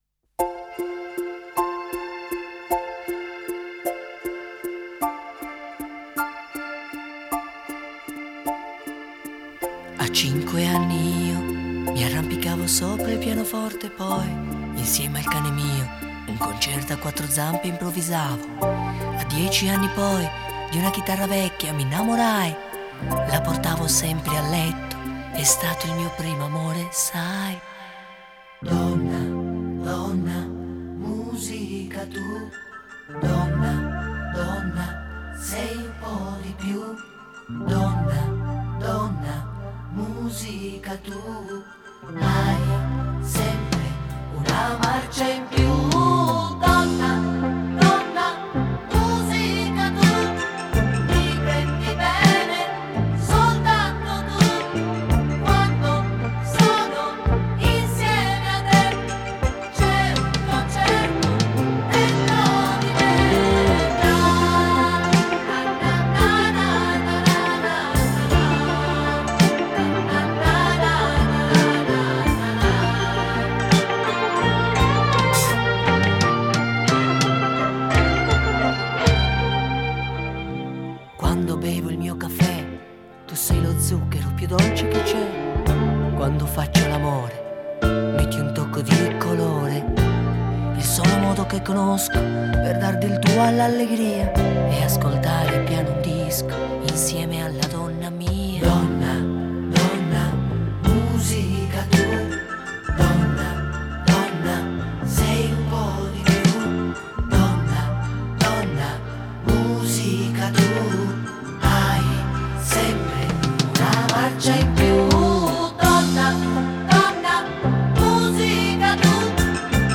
Итальянская эстрада